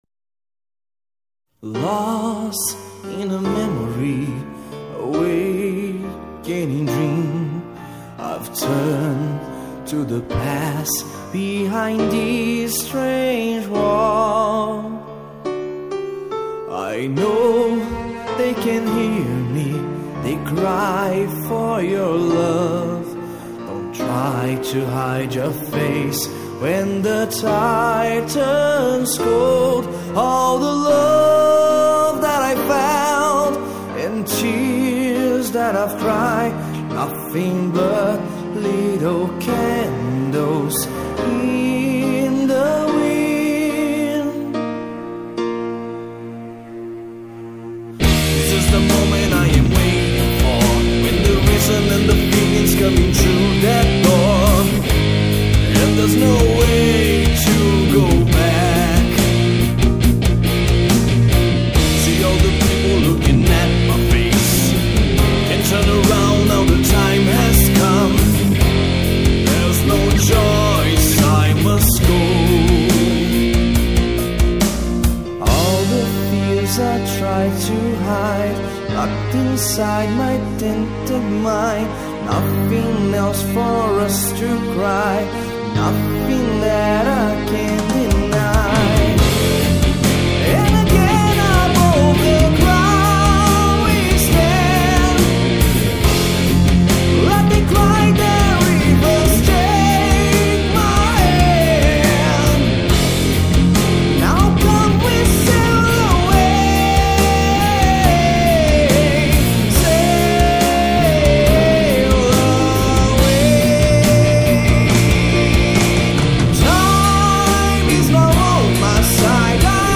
EstiloHeavy Metal